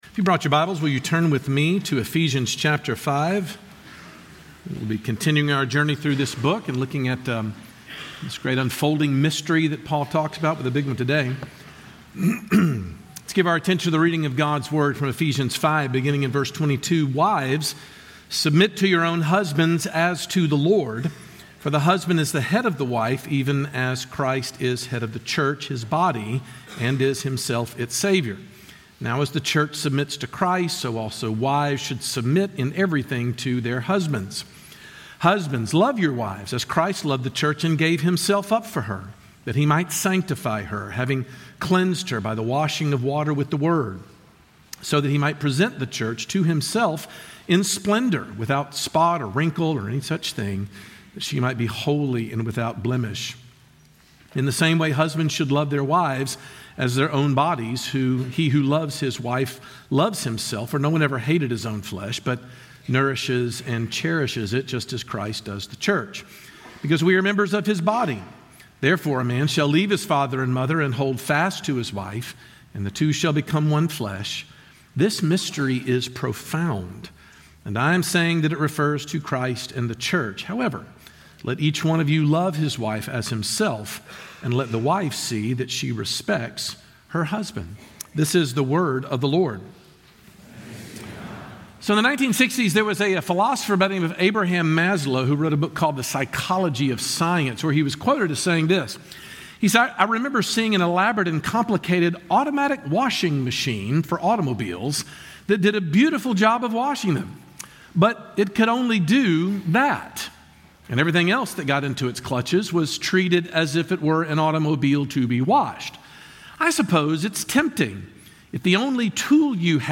When we use marriage for what it was intended, we experience flourishing. Sermon Points: